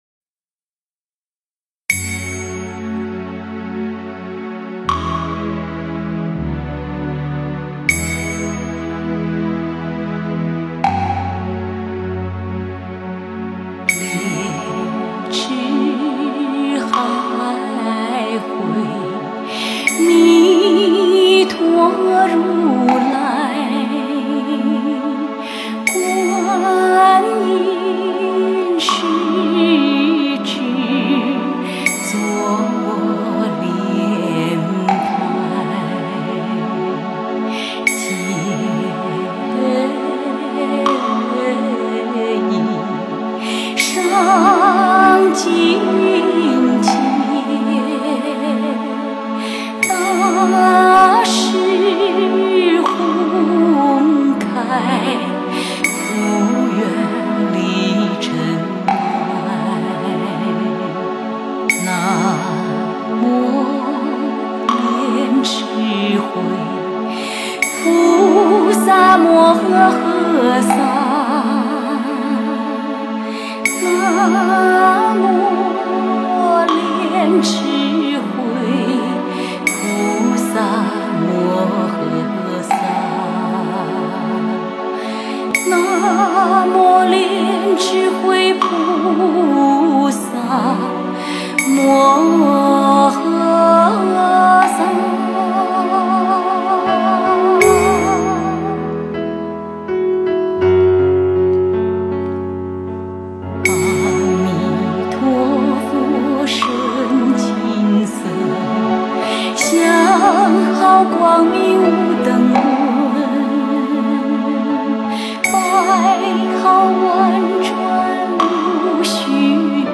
佛音 诵经 佛教音乐 返回列表 上一篇： 飞天吉祥(赞佛偈